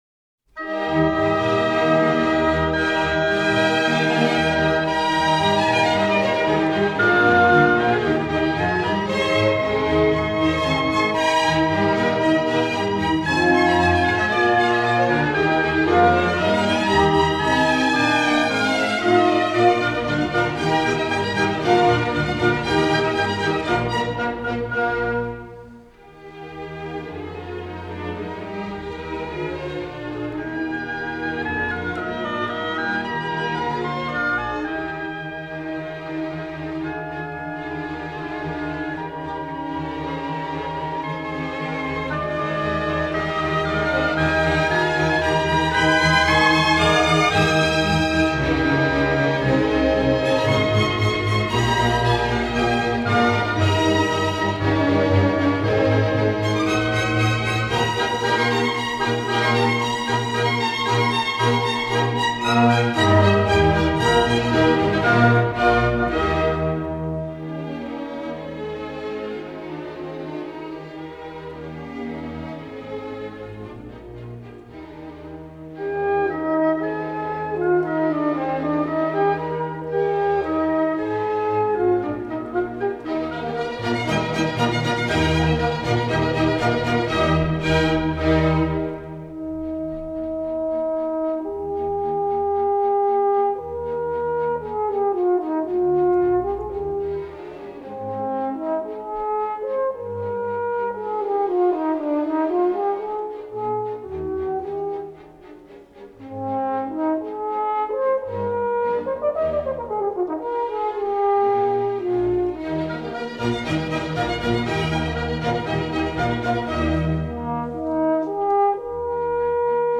- 호른 협주곡 제4번 내림마장조 K.495
제1악장 Allegro moderato    제2악장 Romanza-Andante    제3악장 Rondo Allegro vivace